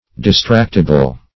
Distractible \Dis*tract"i*ble\, a. Capable of being drawn aside or distracted.